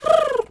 pgs/Assets/Audio/Animal_Impersonations/pigeon_2_call_07.wav at master
pigeon_2_call_07.wav